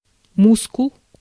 Ääntäminen
IPA: /spiːr/